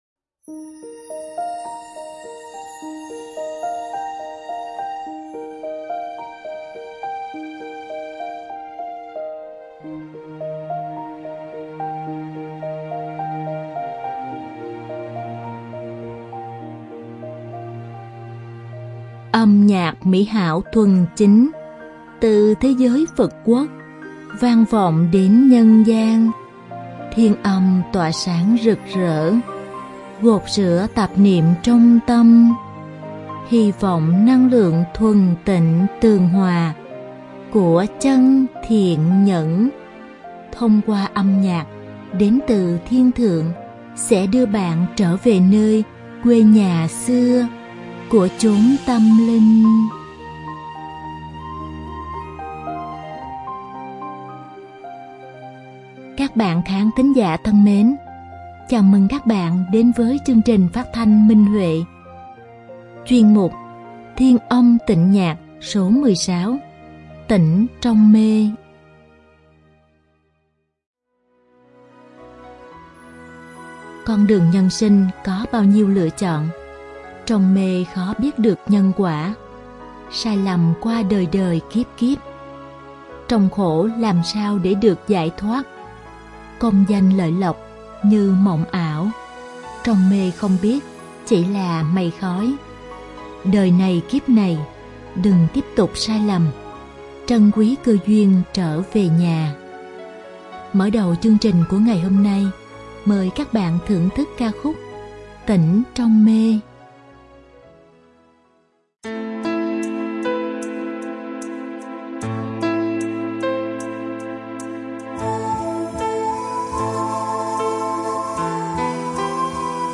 Đơn ca nữ